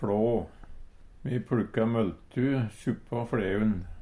fLå store, opne områder i fjellet Eintal ubunde Eintal bunde Fleirtal ubunde Fleirtal bunde ei fLå fLåe fLeu fLeun Eksempel på bruk Me pLukka møLtu suppå fLeun. Høyr på uttala Ordklasse: Substantiv inkjekjønn Attende til søk